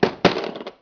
1 channel
ammoBox_drop.wav